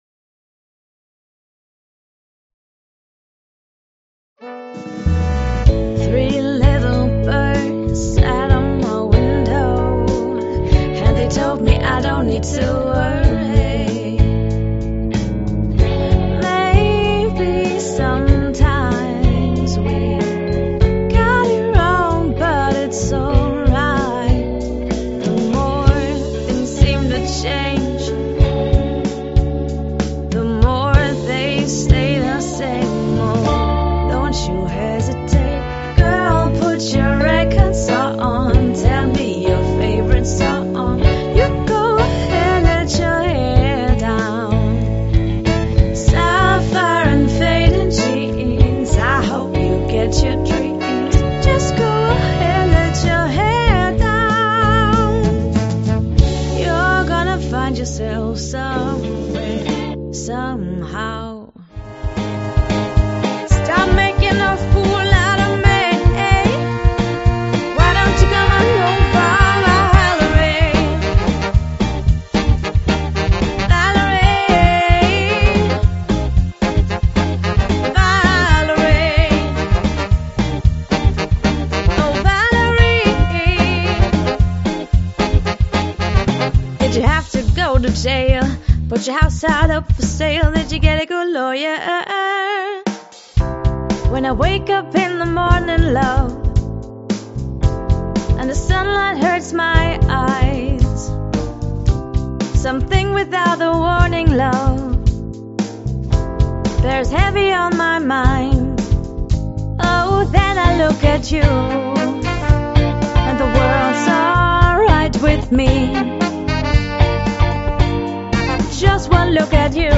8 Musiker:innen